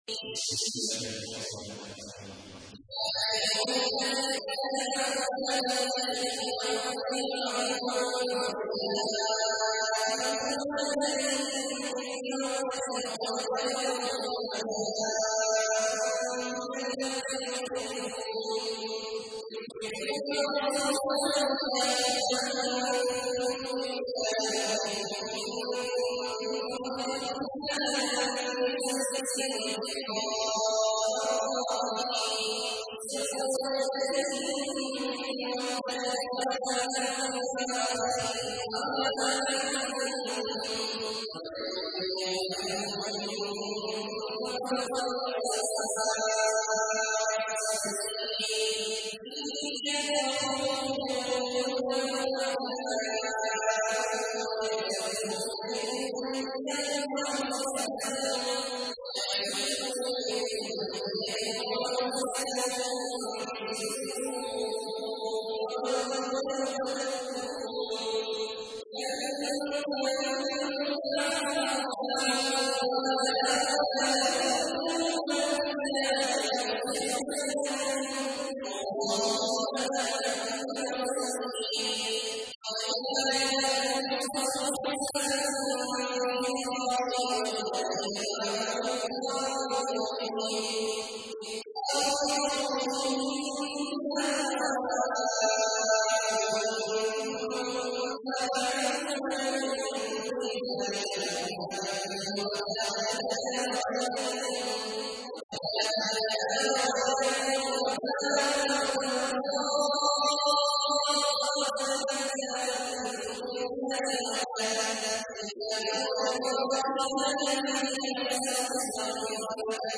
تحميل : 60. سورة الممتحنة / القارئ عبد الله عواد الجهني / القرآن الكريم / موقع يا حسين